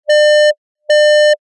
stall-600-chopped.wav